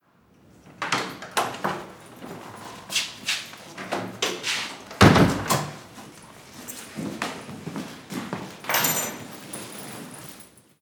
Acción de entrar en una casa con llaves
puerta
cerradura
Sonidos: Acciones humanas
Sonidos: Hogar